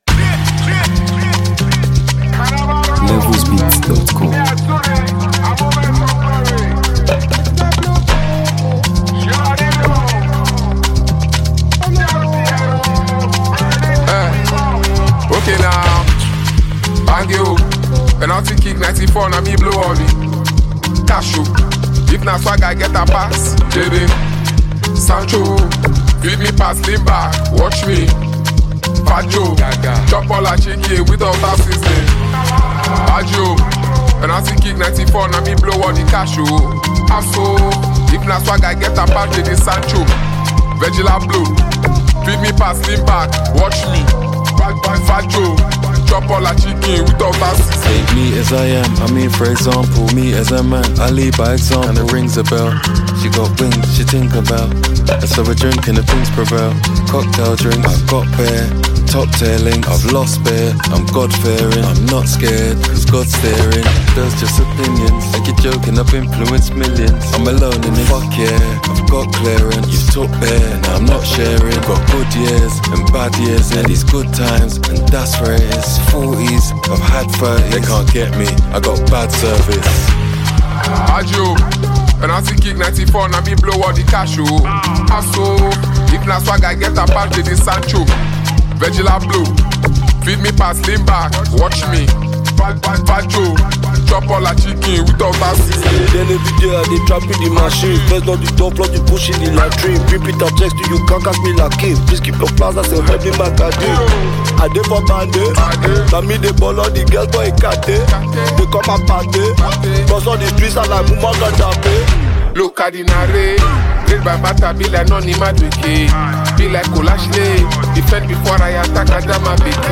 hip-hop
If you’re a true lover of high-quality rap music